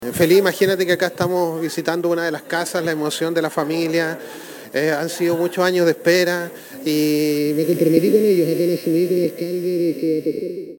En una ceremonia emotiva y llena de alegría, se llevó a cabo la inauguración y entrega de llaves del proyecto de vivienda «Construyendo Juntos», que fue financiado por el Fondo Solidario de Elección de Vivienda, Decreto Supremo 49 del MINVU.
Sobre esto, Denis Cortés Aguilera, alcalde de la comuna, destacó que